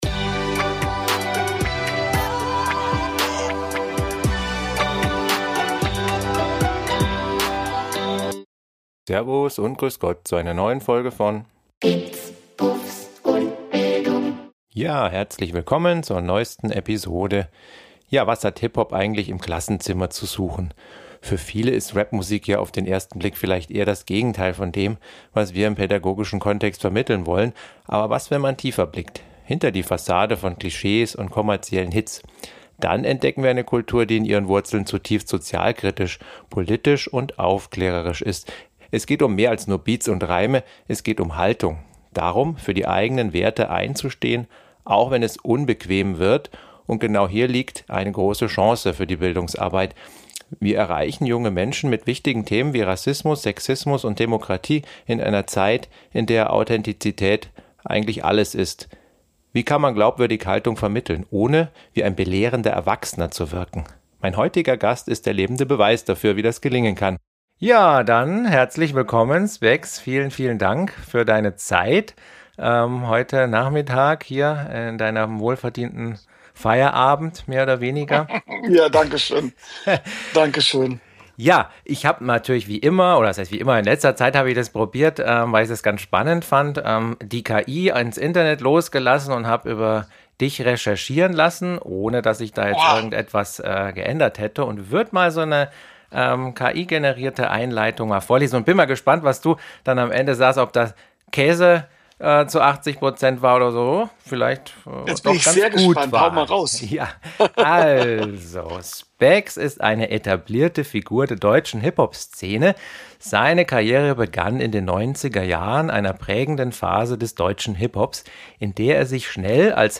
In dieser inspirierenden Episode sprech ich mit Rafael Szulc-Vollmann, besser bekannt als Spax, einer wahren Ikone des deutschen Hip-Hops.
Ein Gespräch über Mut, die Kraft der Glaubwürdigkeit und die ursprünglichen Werte des Hip-Hop.